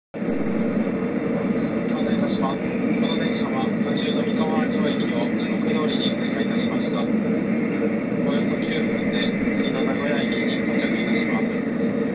미카와안조역을 통과하는 차내
차내 안내 방송